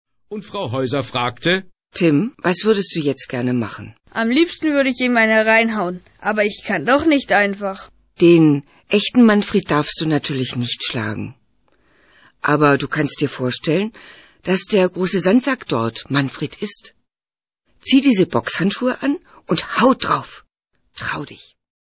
Das Hörbuch "Schutzbär Bulli", CD zum Buch